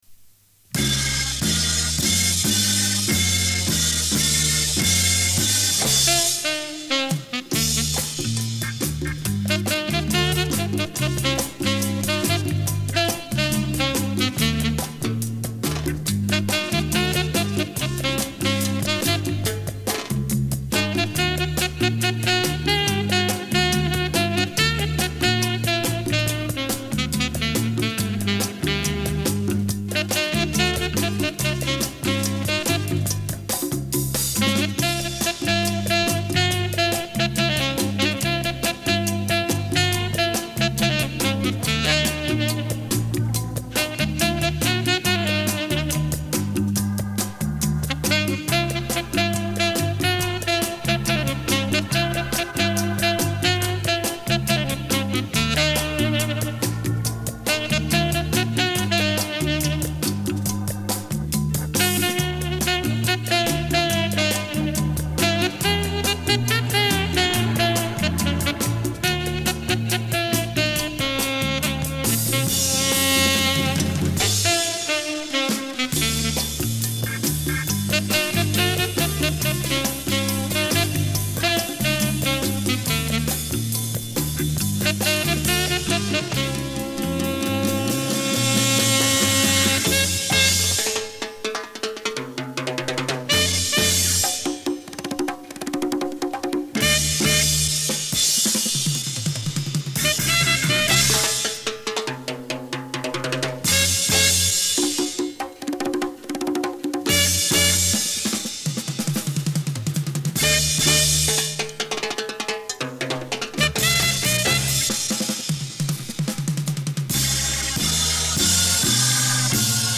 Разбираю кассеты из семейного архива.
Первой идет тестовая кассета для магнитофона Sharp c функцией Tape Program Sensor.